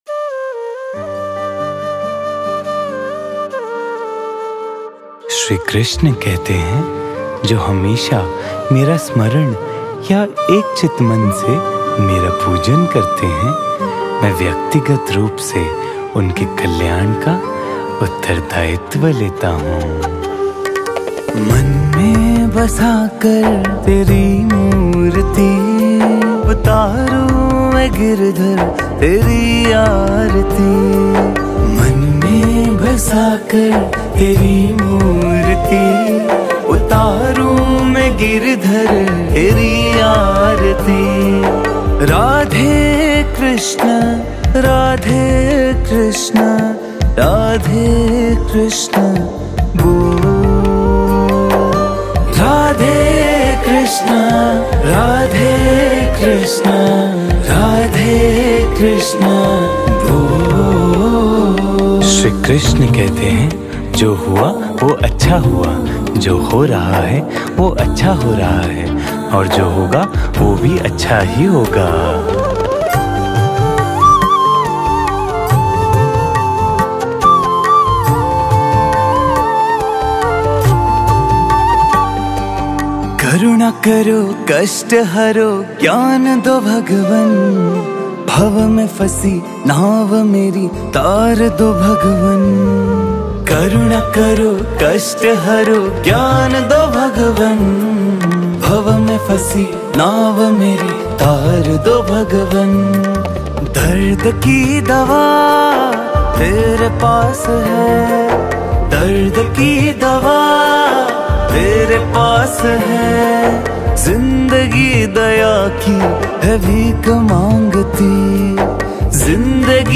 Hindi Bhajan